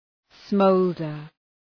Shkrimi fonetik {‘sməʋldər}
smolder.mp3